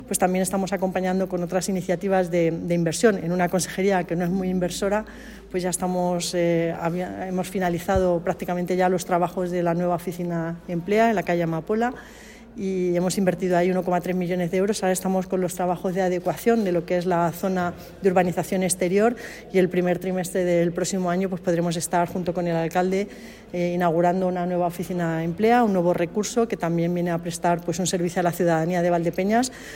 >> Patricia Franco clausura la jornada Culinaria Sala Pro en Valdepeñas
patricia_franco_nueva_oficina_emplea_valdepenas.mp3